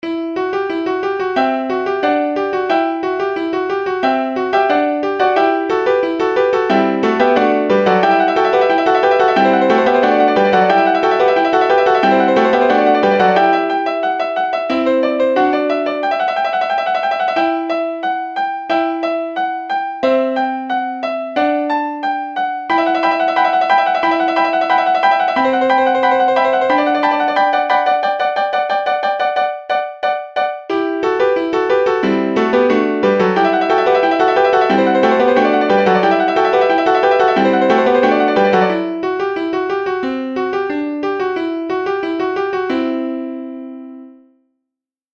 ВИРШИНА МАЕГО ПИАНИННОГО ТВОРЧИСТВА!